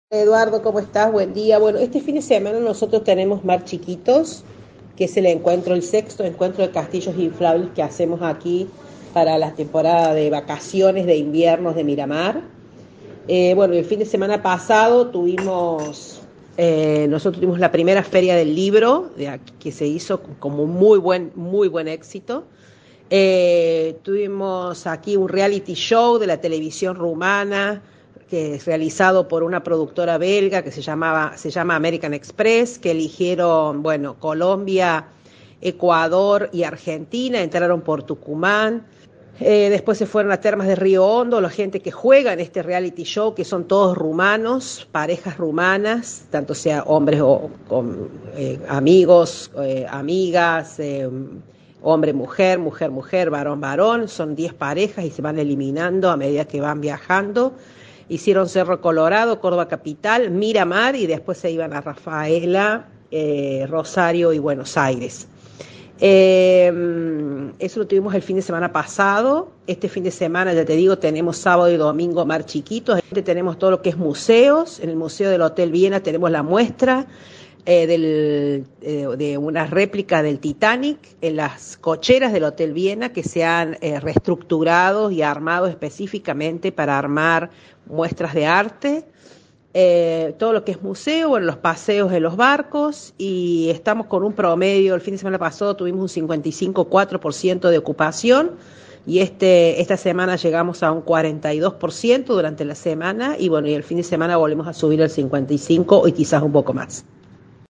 Así lo asegura Silvina Arrieta, secretaria de Turismo de Miramar de Ansenuza: